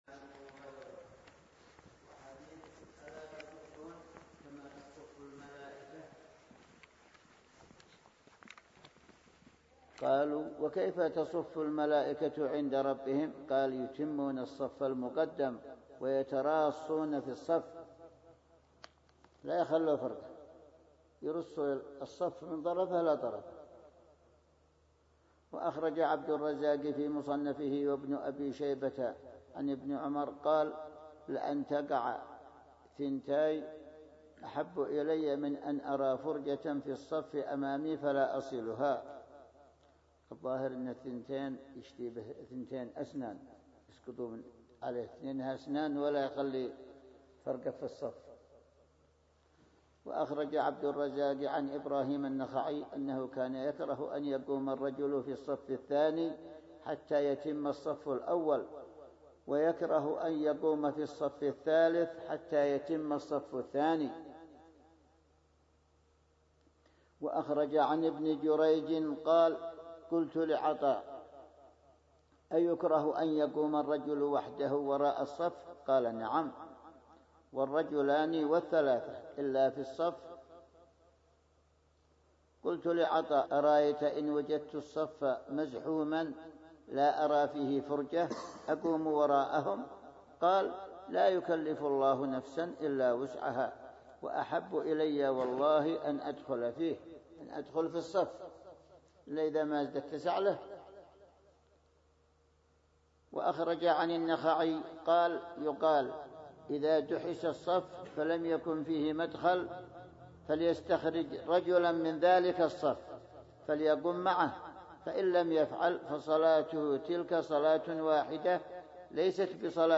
موعظة هامة في يوم الجمعة 22رجب1440